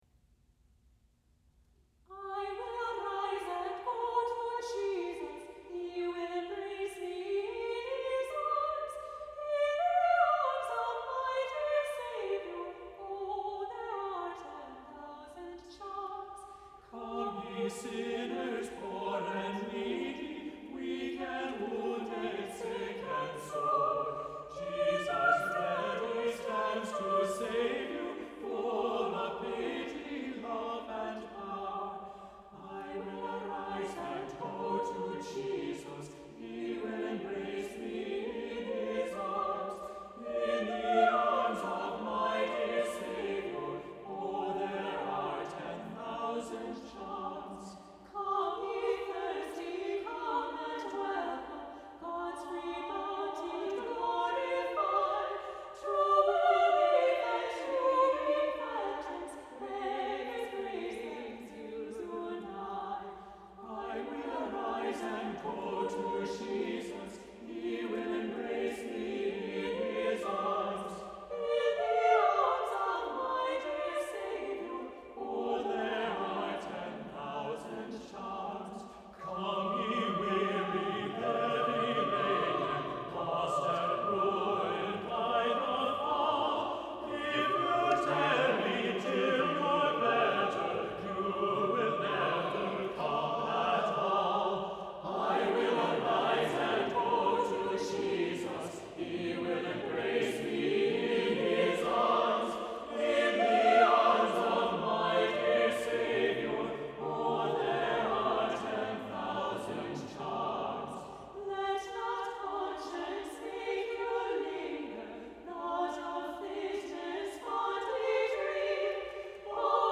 • Music Type: Choral
• Voicing: SATB
• Accompaniment: a cappella
• opens with ladies taking and developing the tune